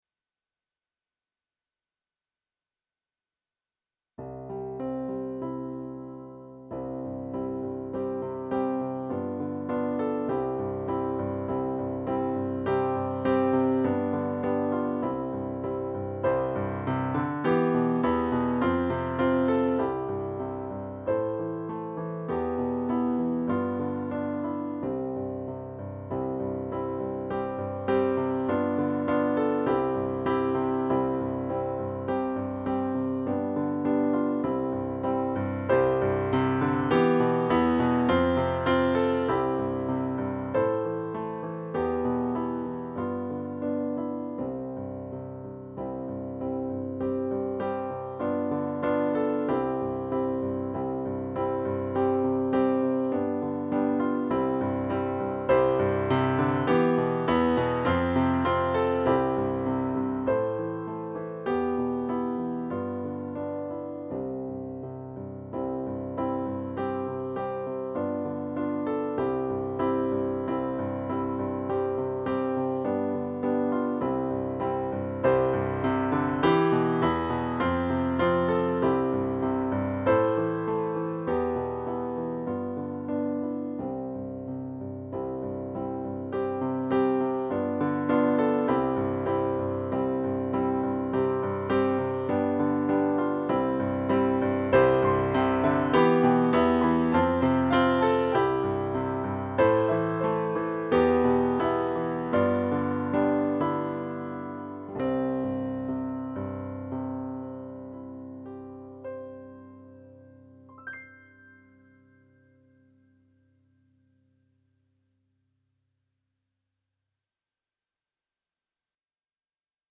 vánoční koledu